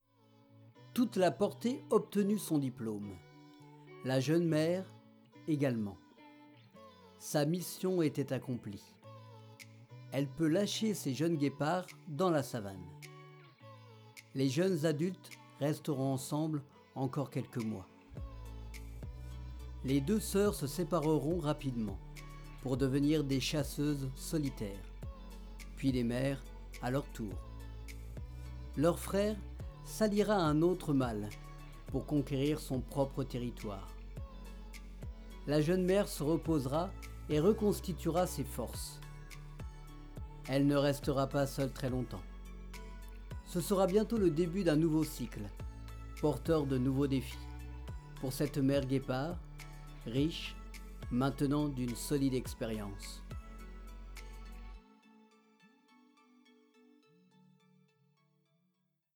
Voix off
35 - 55 ans - Baryton